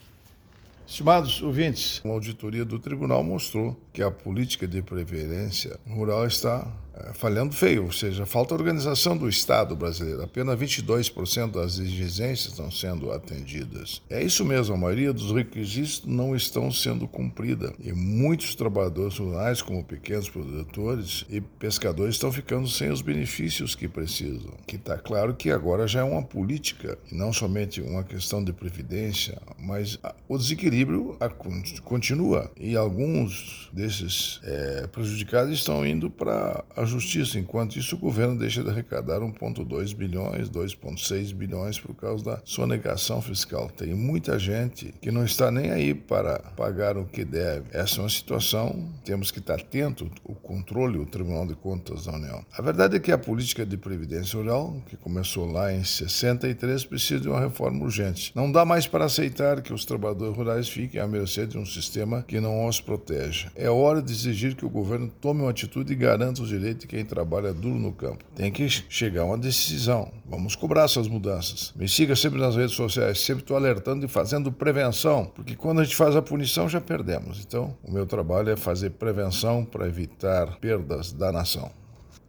02-Ministro-prog-radio-politica-de-previdencia-rural-esta-falhando.mp3.mp3